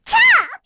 Lilia-Vox_Attack3_kr.wav